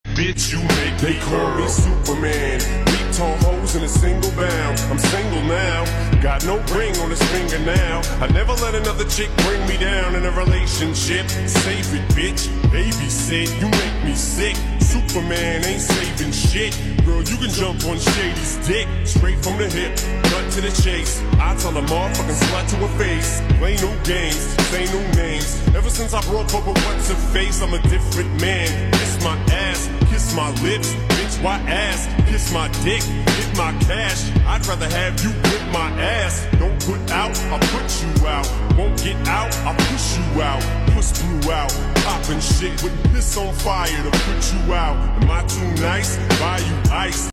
Best UFC Crowd Cam 🥶 Sound Effects Free Download